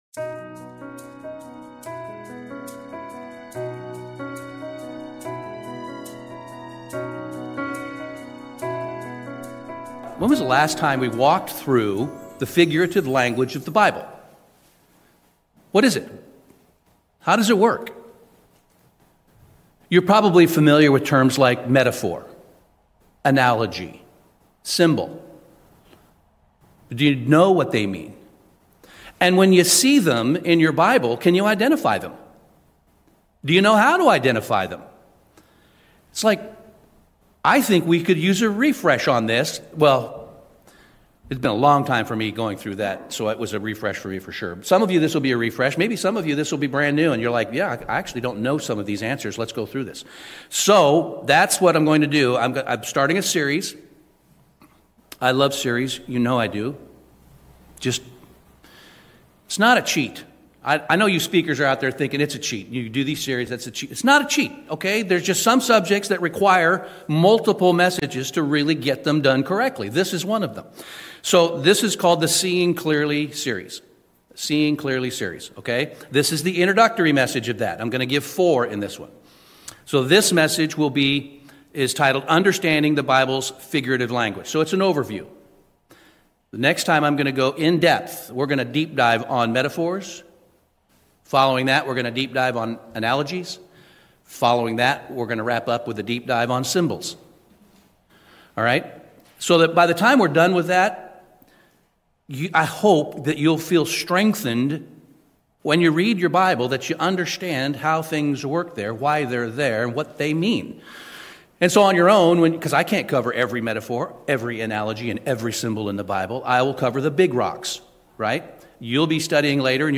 Understanding the Bible's Figurative Language | United Church of God